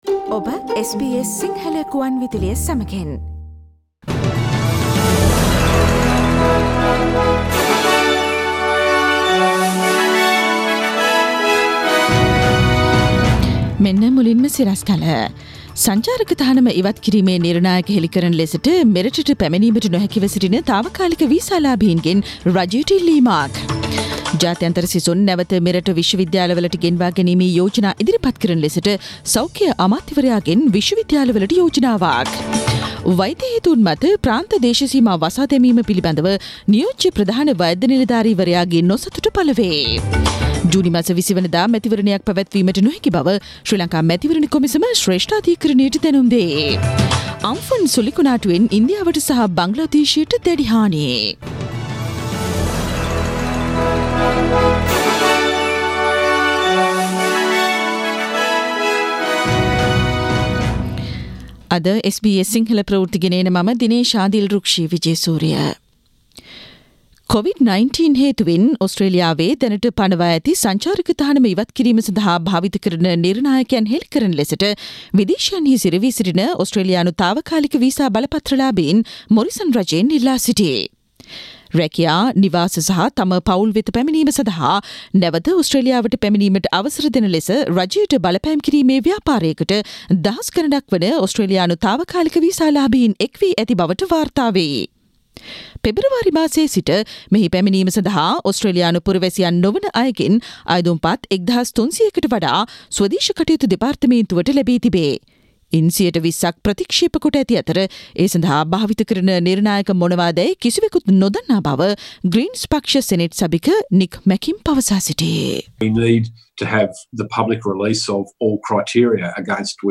Daily News bulletin of SBS Sinhala Service: Thursday 21 May 2020
Today’s news bulletin of SBS Sinhala radio – Thursday 21 May 2020.